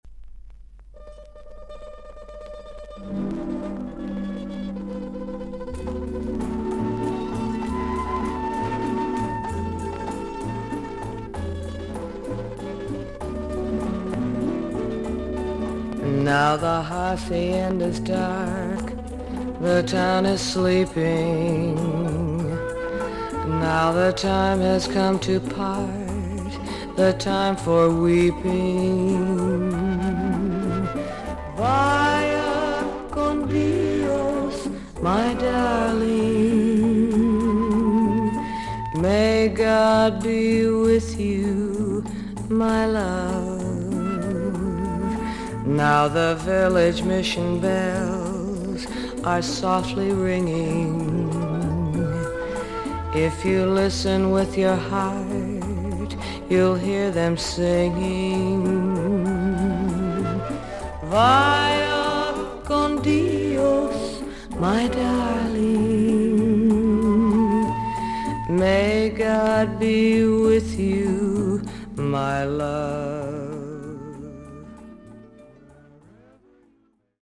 セクシーに囁くように歌う女性シンガー。
VG+ 少々軽いパチノイズの箇所あり。少々サーフィス・ノイズあり。